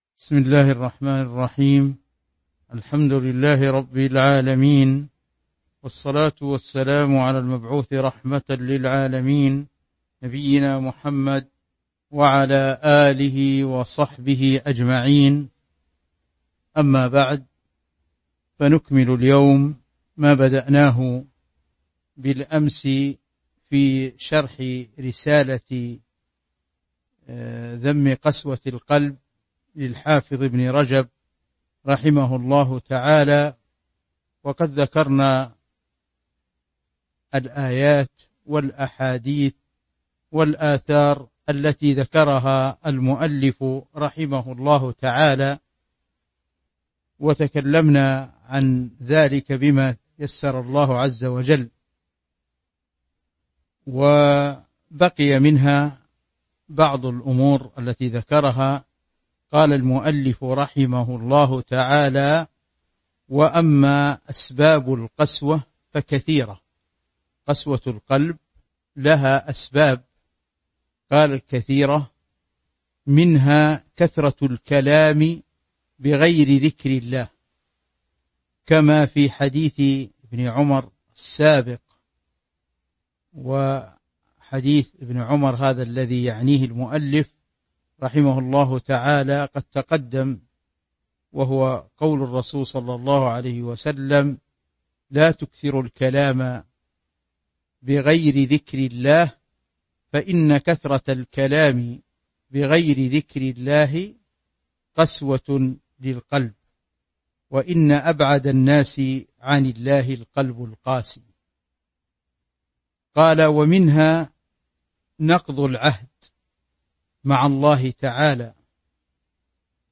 تاريخ النشر ٨ ذو القعدة ١٤٤٢ هـ المكان: المسجد النبوي الشيخ